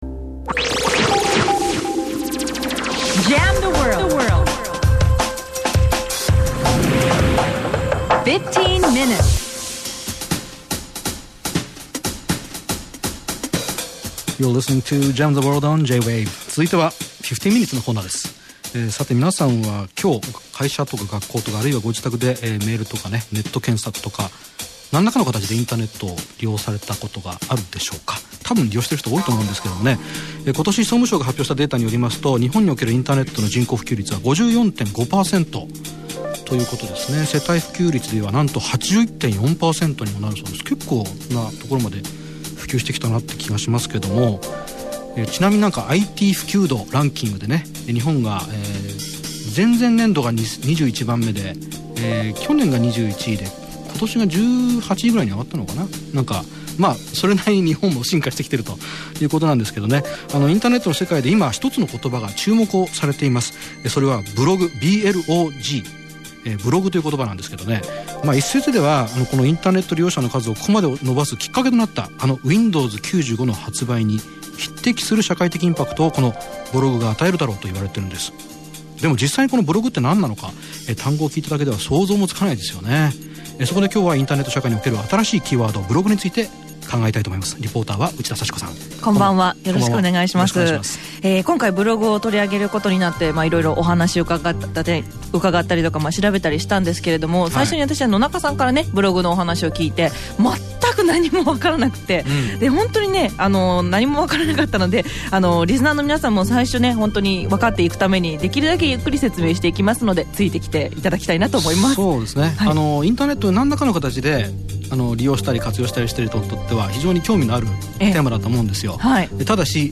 The J-Wave interview was a blast.
J-Wave is the 3rd largest radio station in Japan and has a lot of reach so I hope we have some impact.